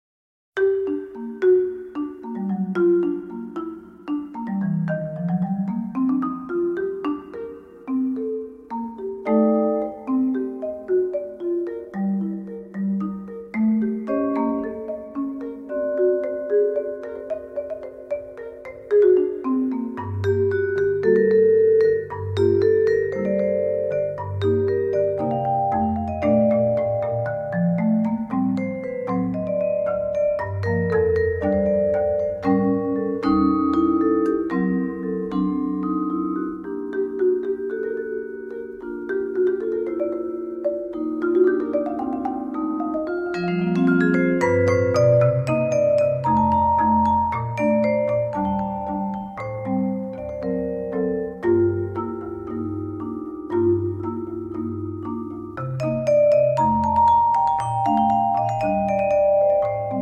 chamber percussion group
Vibraphone and Marimba